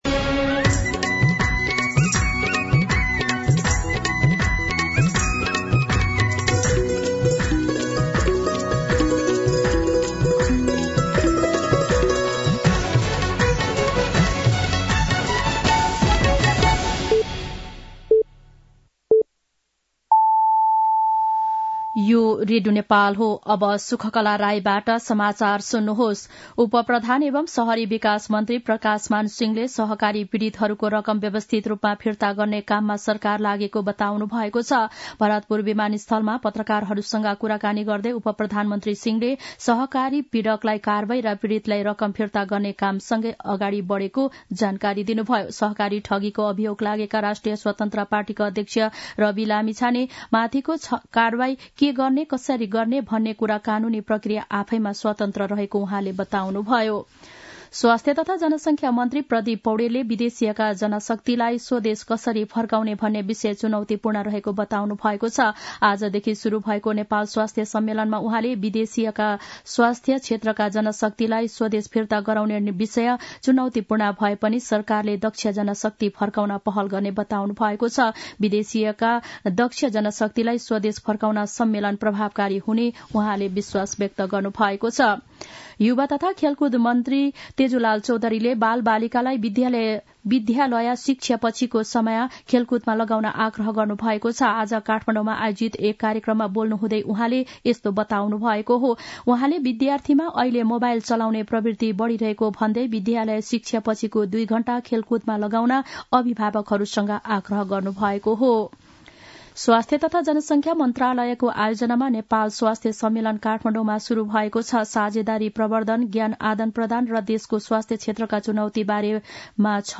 दिउँसो १ बजेको नेपाली समाचार : १२ पुष , २०८१
1-pm-Nepali-News-3.mp3